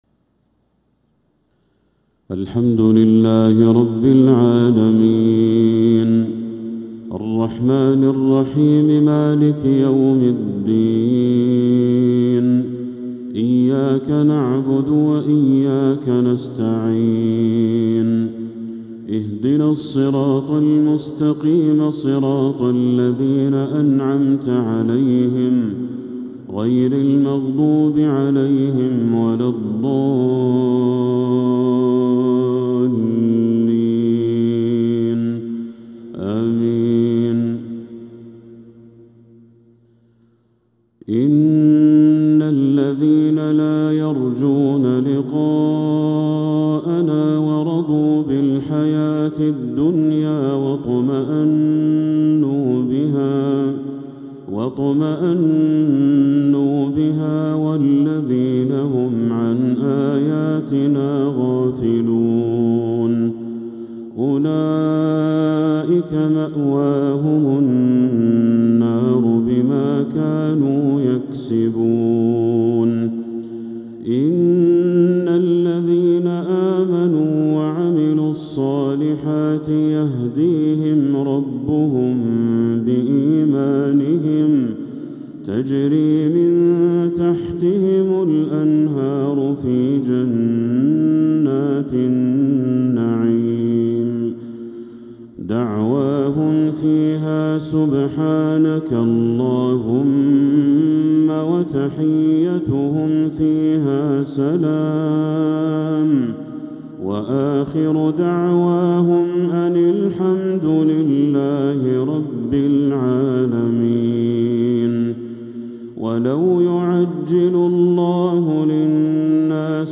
مغرب الثلاثاء 13 محرم 1447هـ | من سورة يونس 7-14 | Maghrib Prayer from Surat yunus 8-7-2025 > 1447 🕋 > الفروض - تلاوات الحرمين